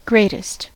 greatest: Wikimedia Commons US English Pronunciations
En-us-greatest.WAV